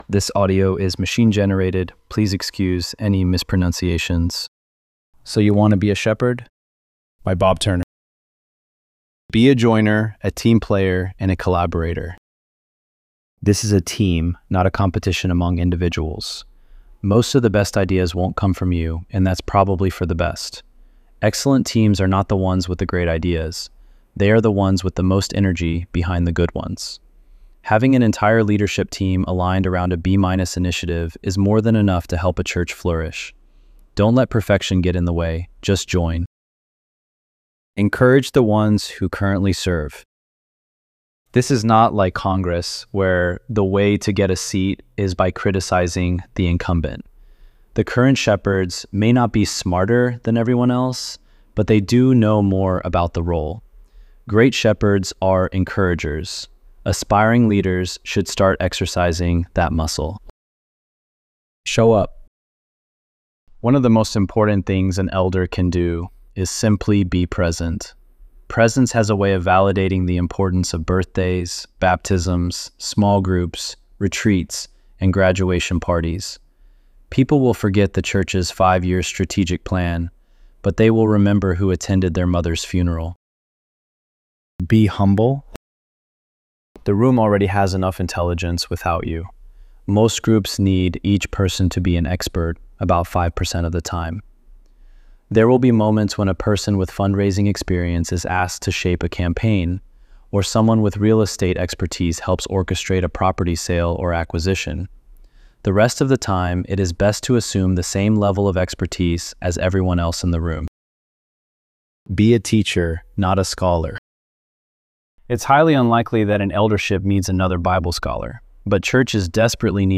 ElevenLabs_3_15.mp3